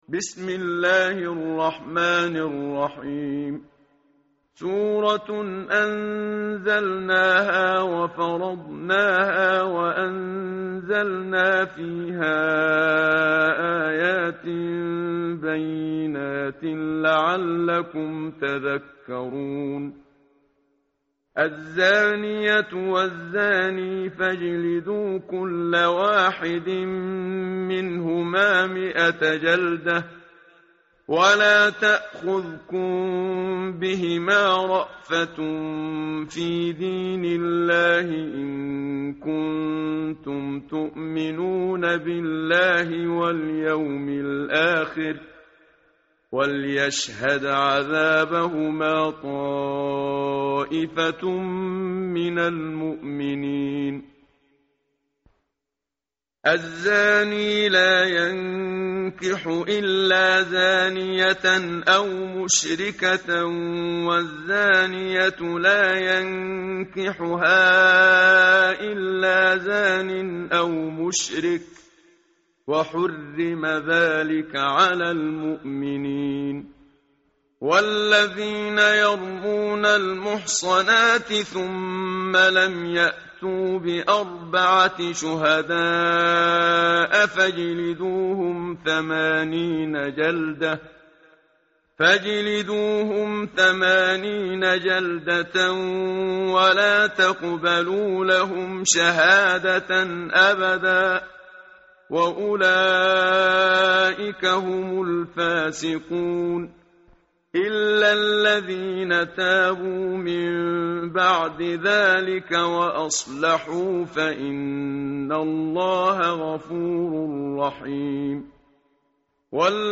متن قرآن همراه باتلاوت قرآن و ترجمه
tartil_menshavi_page_350.mp3